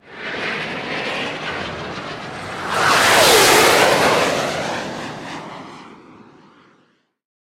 missile.ogg